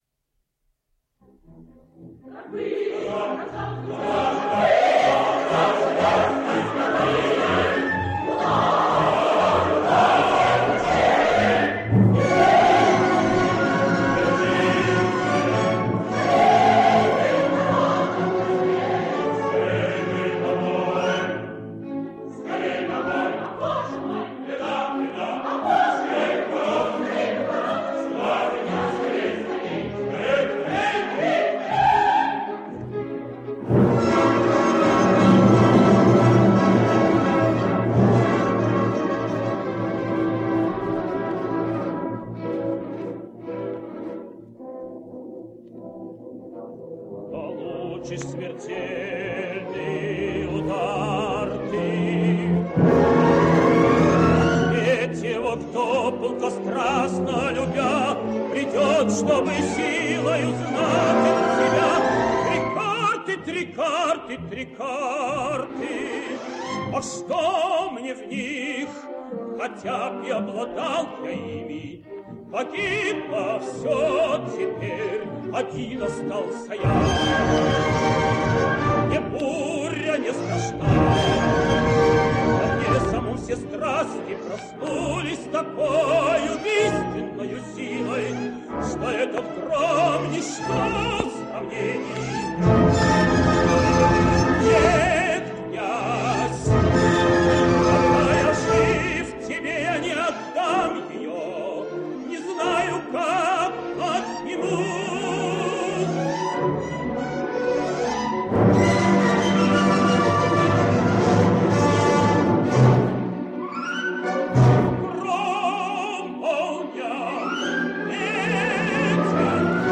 Ukrainian tenor.
Storm / Queen – Spades / 19xx – Georgi Nelepp